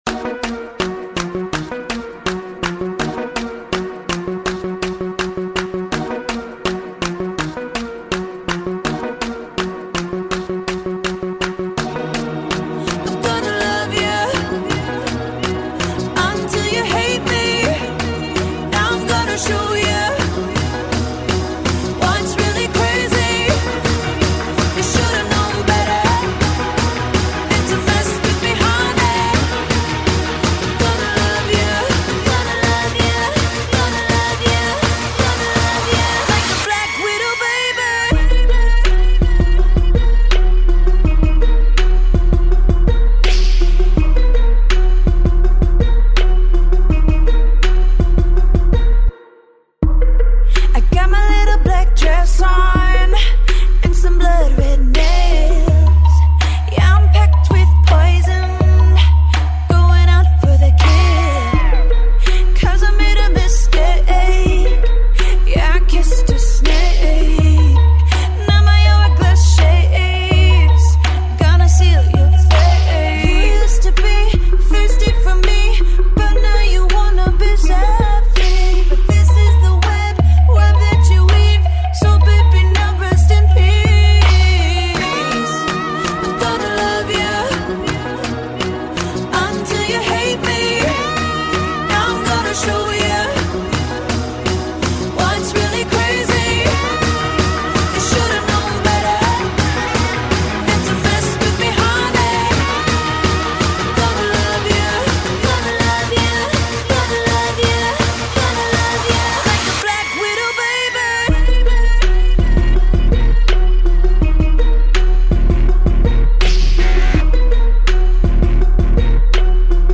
The song is an upbeat vibe that starts in a high tone.